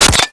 auto_hit_glass1.wav